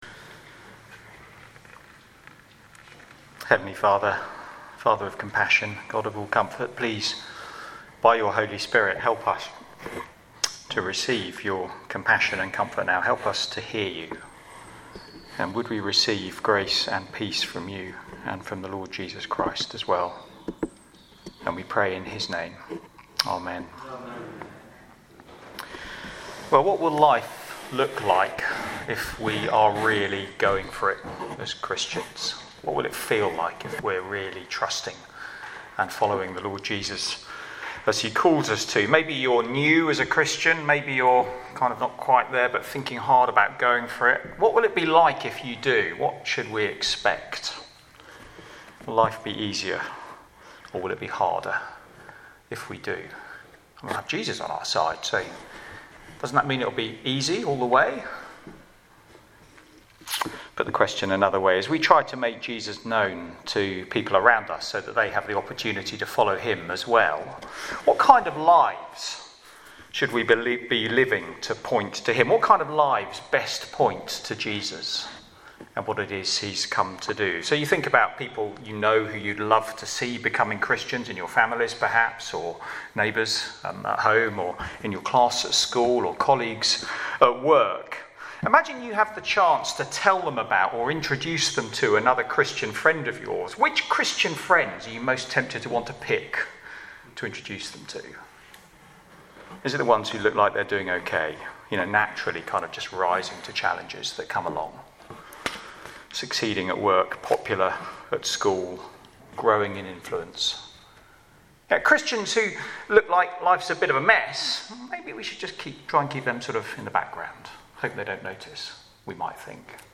Media for Sunday Evening on Sun 10th Sep 2023 18:00
Sermon